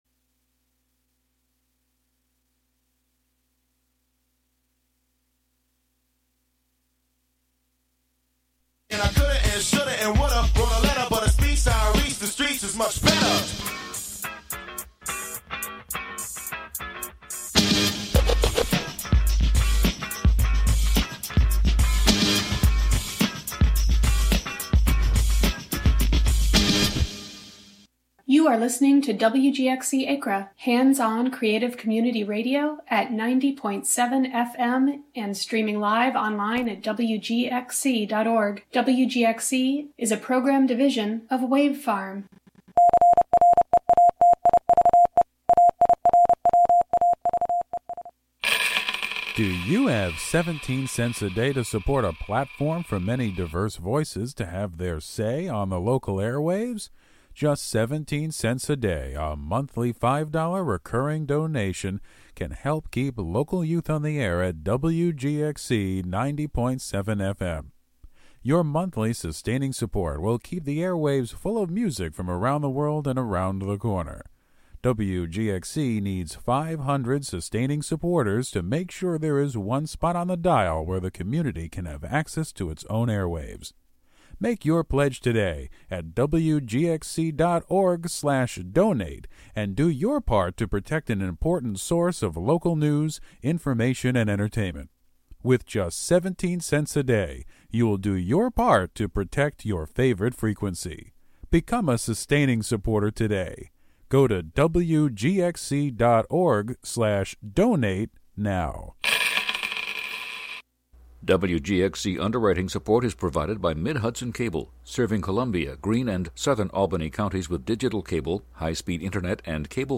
7pm Monthly program featuring music and interviews.